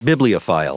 Prononciation du mot bibliophile en anglais (fichier audio)
Prononciation du mot : bibliophile